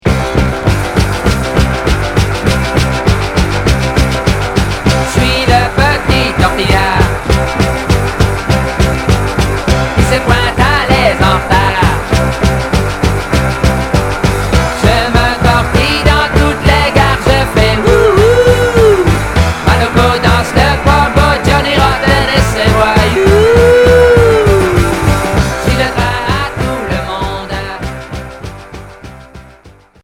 Rock punk Deuxième 45t